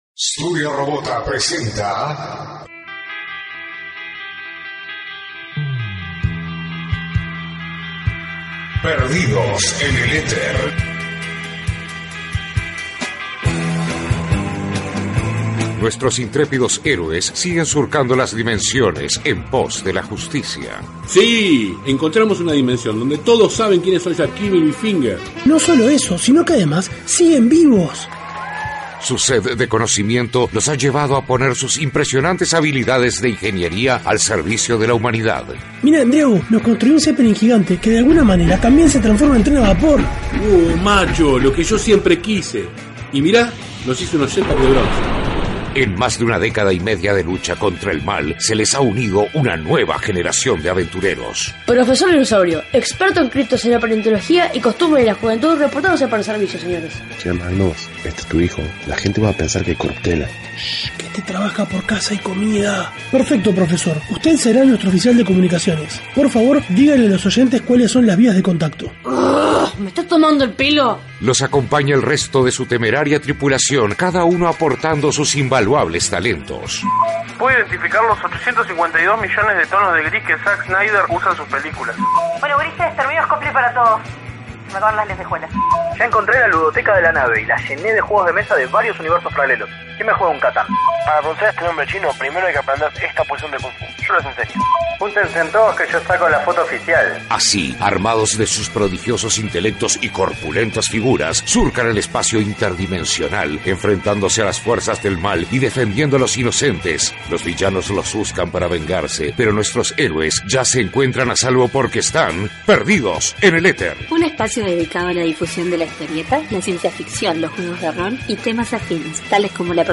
Nuestros enviados también hicieron varias entrevistas